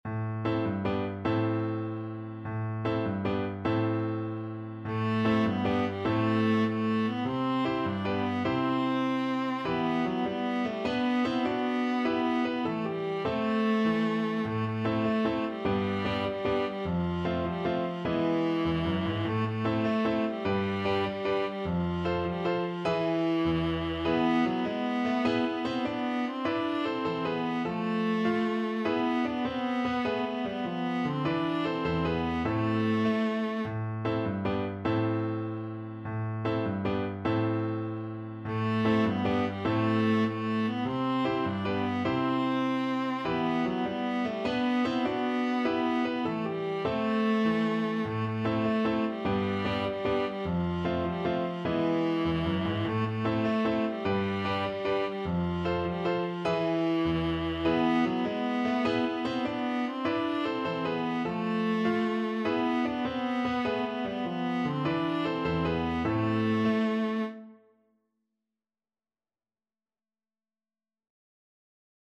Viola
Traditional Music of unknown author.
6/8 (View more 6/8 Music)
A minor (Sounding Pitch) (View more A minor Music for Viola )
With energy .=c.100
Classical (View more Classical Viola Music)